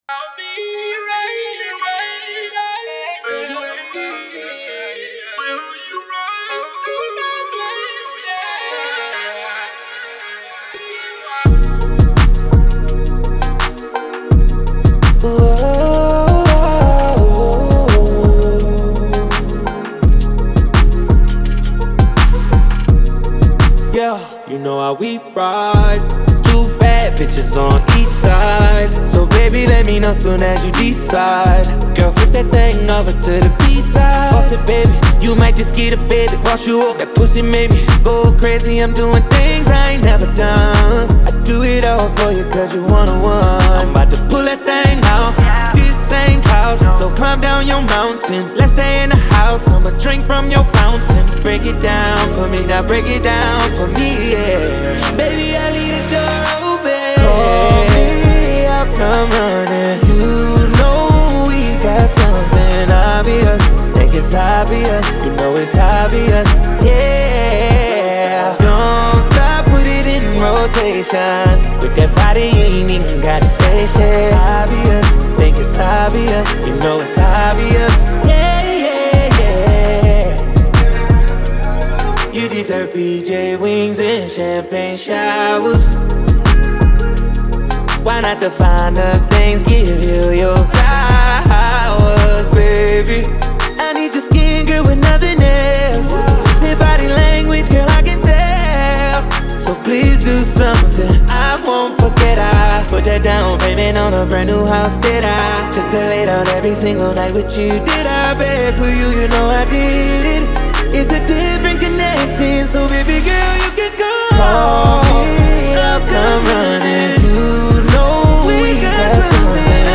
BPM84-84
Audio QualityPerfect (High Quality)
R&B song for StepMania, ITGmania, Project Outfox
Full Length Song (not arcade length cut)